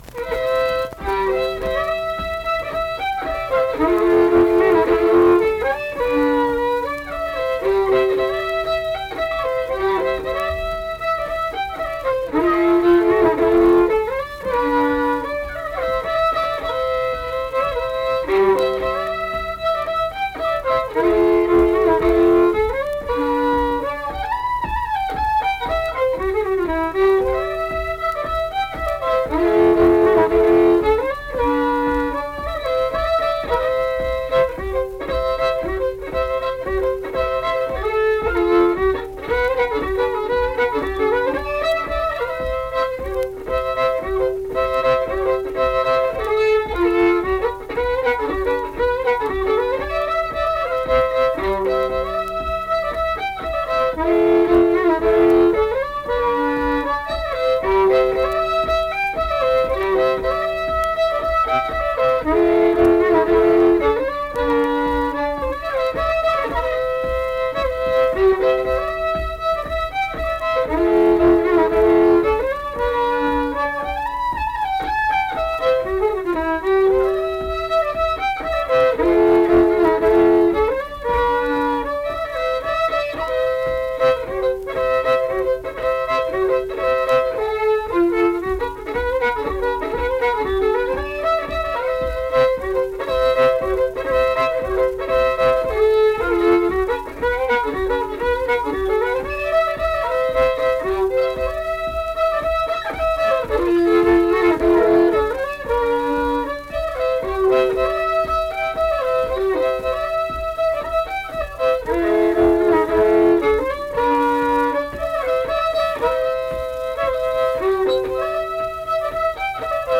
Accompanied guitar and unaccompanied fiddle music performance
Verse-refrain 4(2).
Instrumental Music
Fiddle